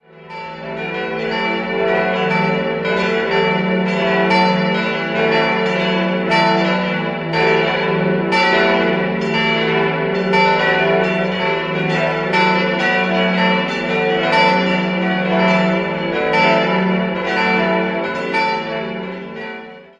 4-stimmiges Geläute: e'-fis'-a'-h'-cis''-e'' Die große Glocke wurde im Jahr 1948 von der Gießerei Causard-Sleghers in Tellin (Belgien) gegossen, die fünf kleineren sind Werke von Rudolf Perner (Passau) aus dem Jahr 1964.